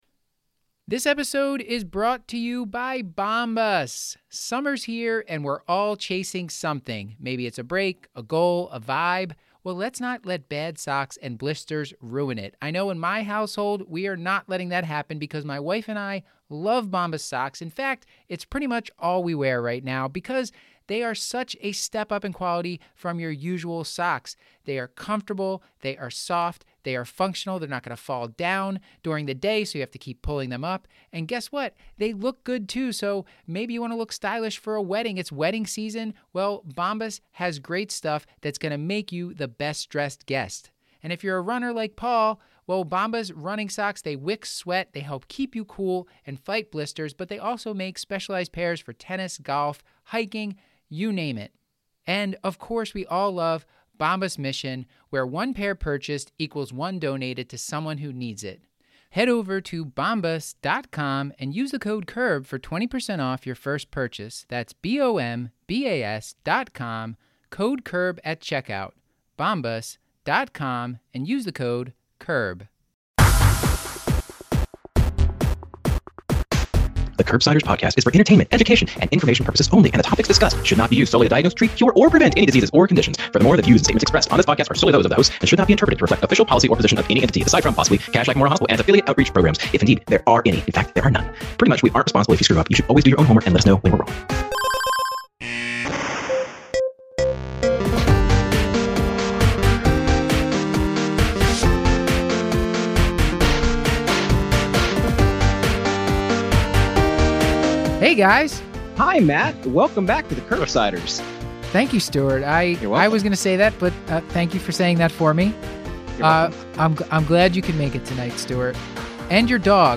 Dr Peter Attia, a physician focusing on the applied science of longevity, walks us through lifespan and healthspan, exploring tactics aimed at extending both. Listeners will challenge the conventional approach to primary care and explore tactics to optimize exercise, nutrition, and health, including counseling on different dietary patterns, fasting, exercise, emotional well-being and how to prepare for the Centenarian Olympics!